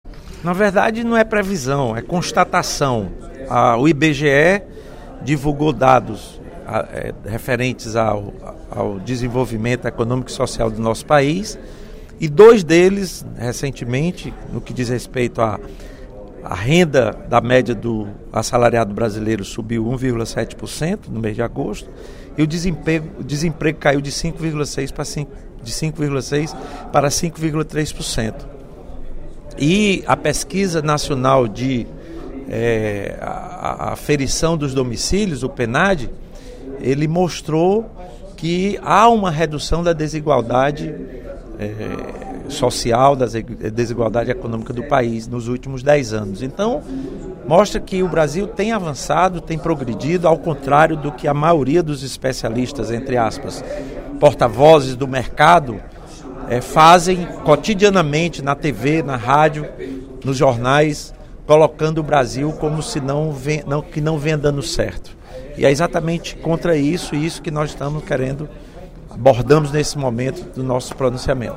O deputado Lula Morais (PCdoB) afirmou, durante o primeiro expediente da sessão plenária desta terça-feira (01/10) da Assembleia Legislativa, que os resultados apontados pelo Instituto Brasileiro de Geografia e Estatística (IBGE) de agosto estão frustrando os pessimistas, “que sempre preveem o pior para o País.